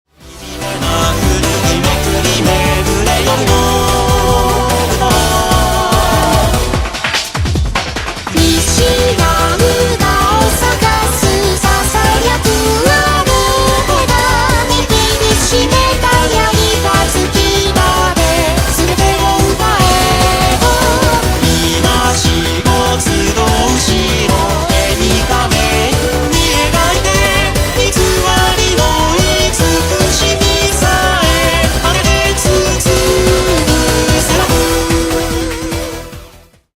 Вокалоиды